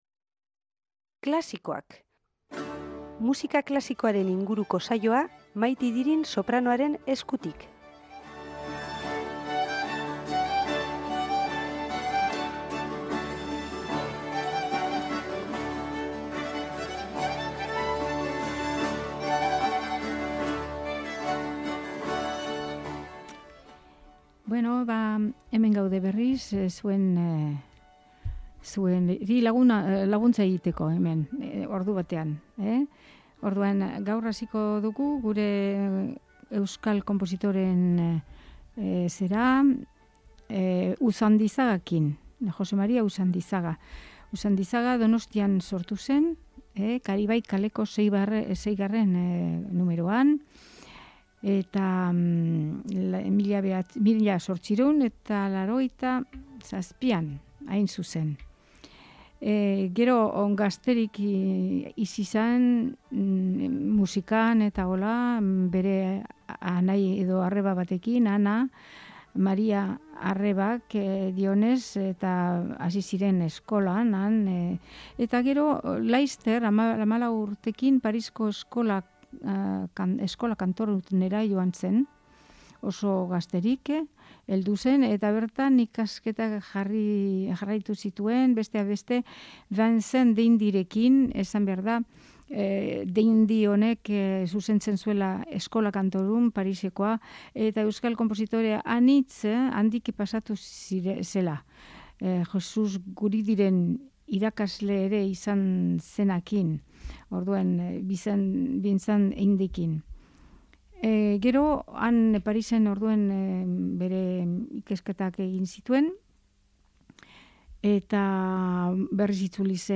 musika klasikoaren saioa